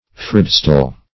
Search Result for " fridstol" : The Collaborative International Dictionary of English v.0.48: Fridstol \Frid"stol`\ (fr[i^]d"st[=o]l`), Frithstool \Frith`stool"\ (fr[i^]th"st[=oo]l`), n. [AS. fri[eth]st[=o]l. See Fred , and Stool .] A seat in churches near the altar, to which offenders formerly fled for sanctuary.